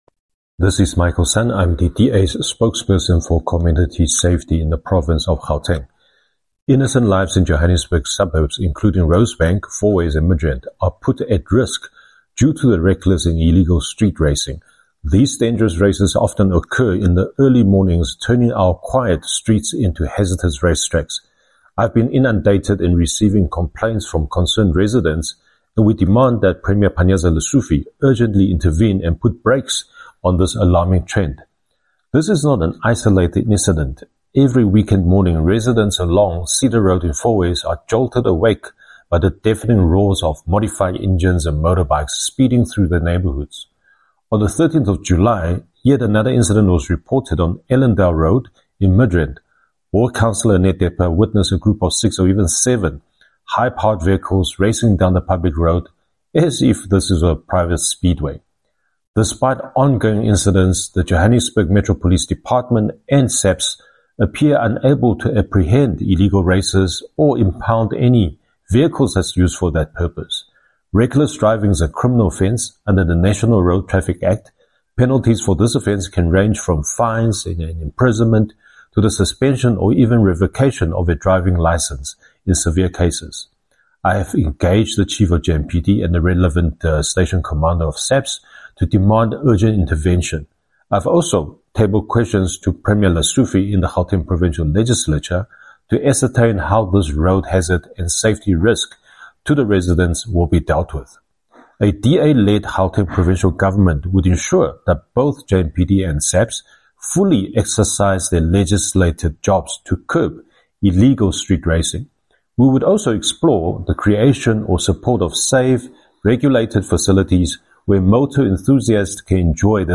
soundbite by Michael Sun MPL.